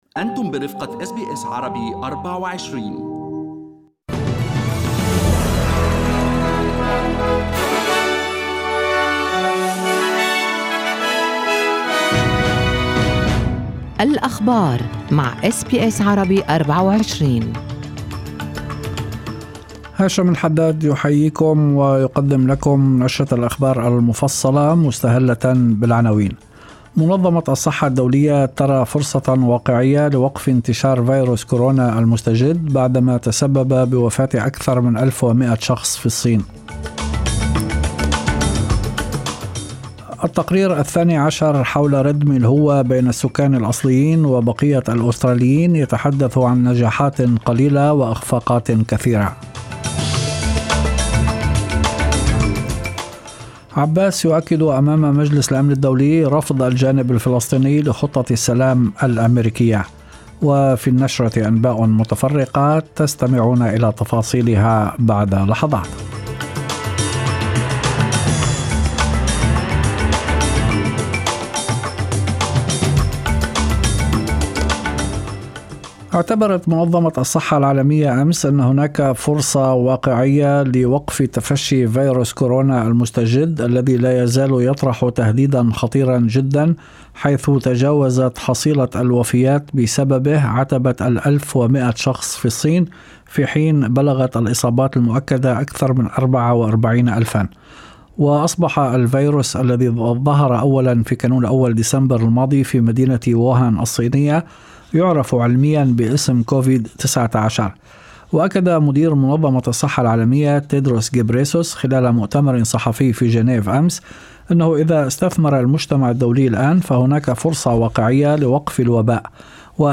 نشرة أخبار المساء 12/02/2020
Arabic News Bulletin Source: SBS Arabic24